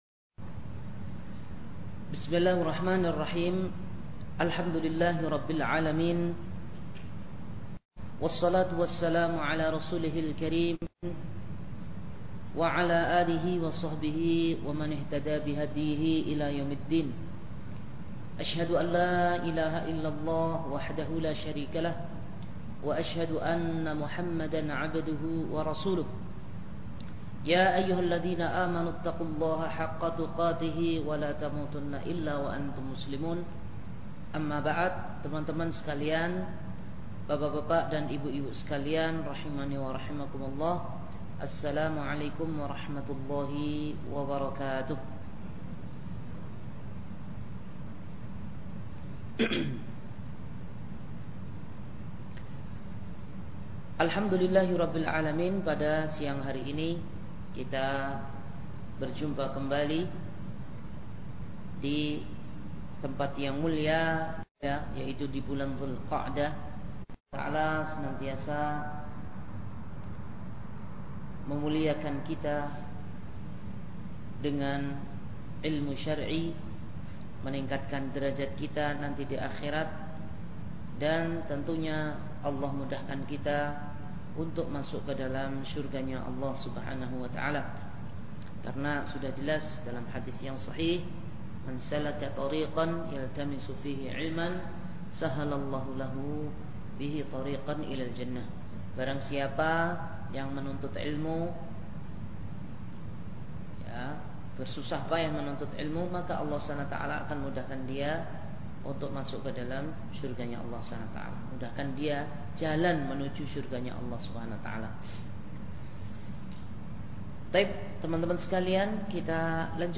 Kajian Sabtu – Barwa Village Barwa Village